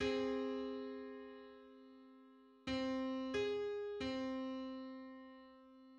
File:Eight-hundred-fifth harmonic on C.mid - Wikimedia Commons
Just: 805/512 = 783.41 cents.
Public domain Public domain false false This media depicts a musical interval outside of a specific musical context.
Eight-hundred-fifth_harmonic_on_C.mid.mp3